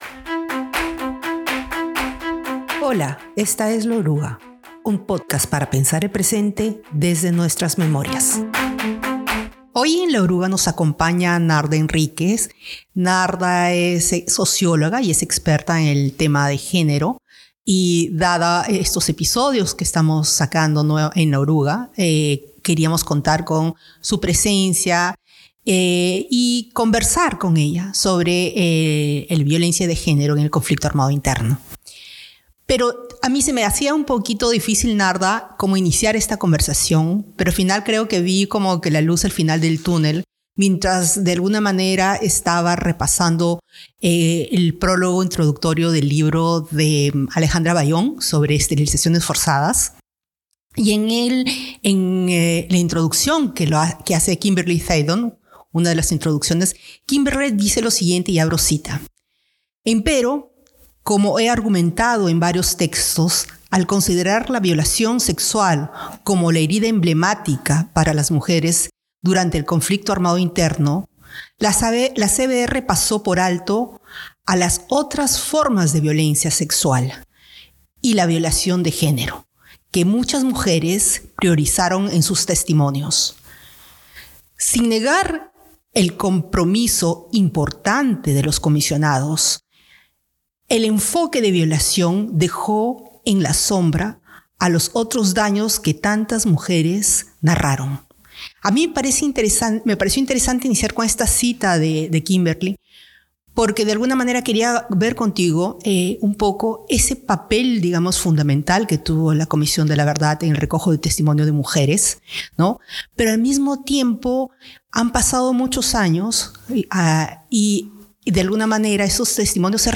Conversando